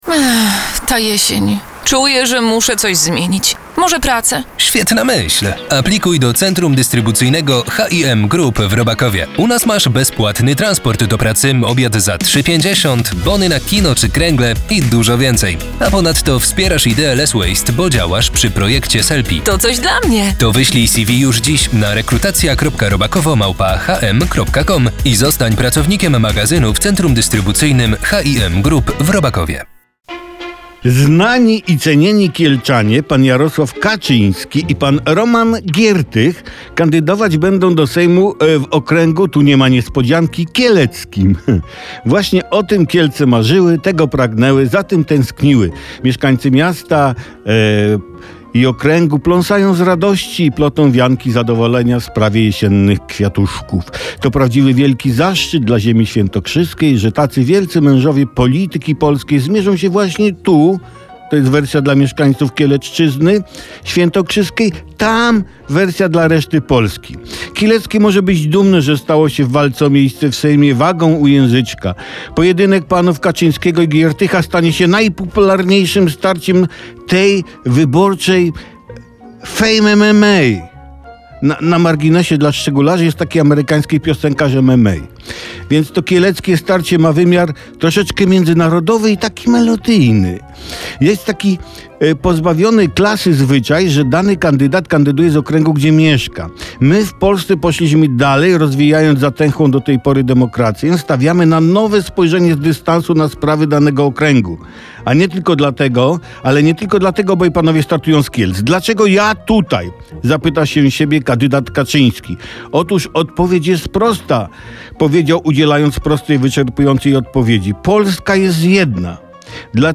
Felieton Tomasza Olbratowskiego